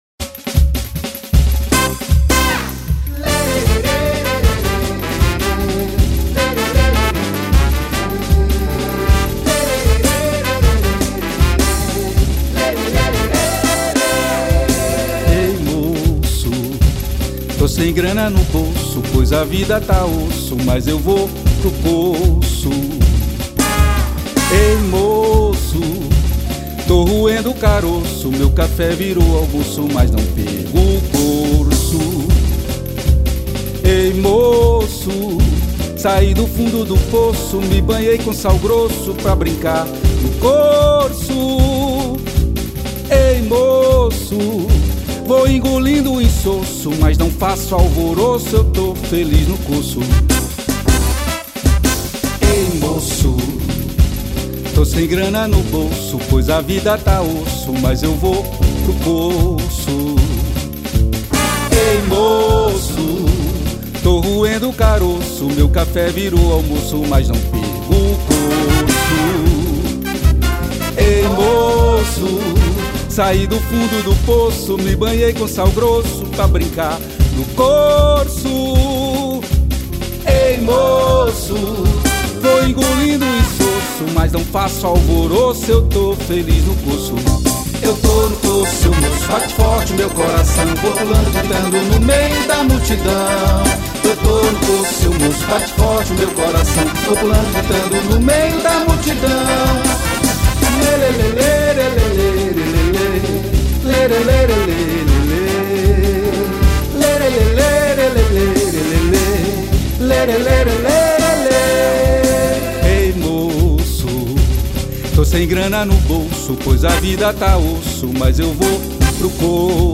601   03:08:00   Faixa:     Frevo